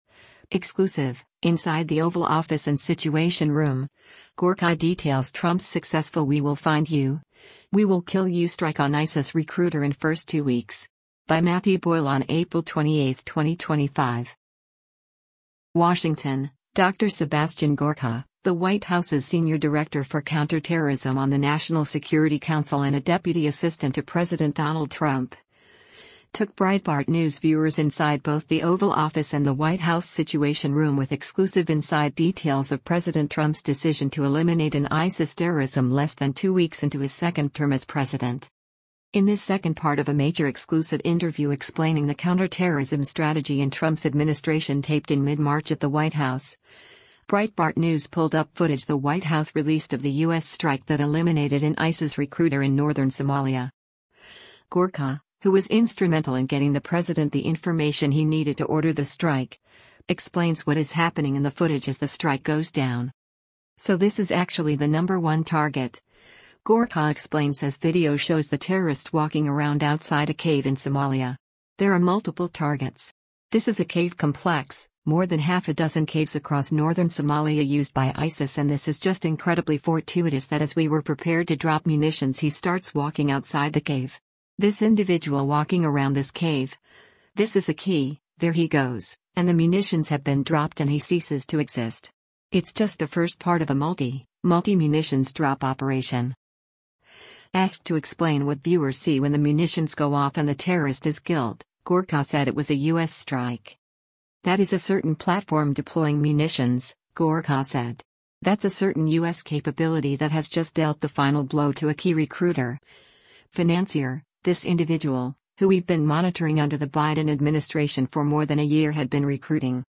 In this second part of a major exclusive interview explaining the counterterrorism strategy in Trump’s administration taped in mid-March at the White House, Breitbart News pulled up footage the White House released of the U.S. strike that eliminated an ISIS recruiter in northern Somalia.